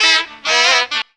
FUNKY RIFF.wav